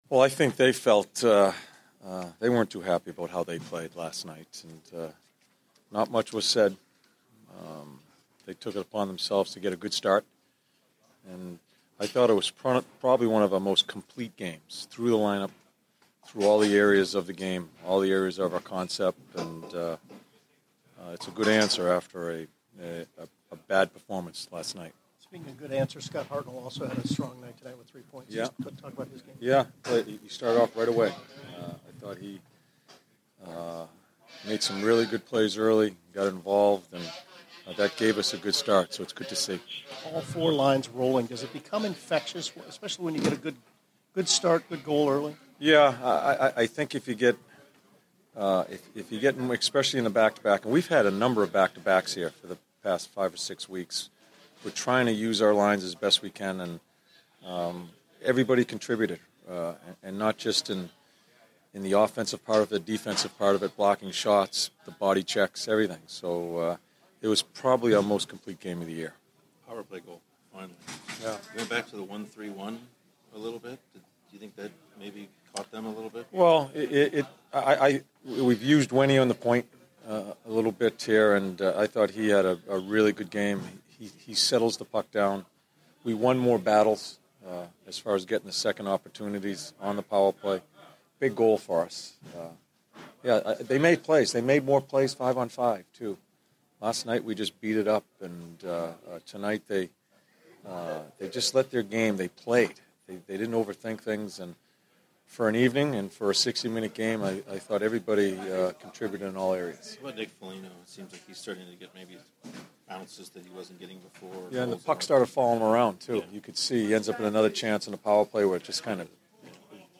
John Tortorella Post-Game 12/05/15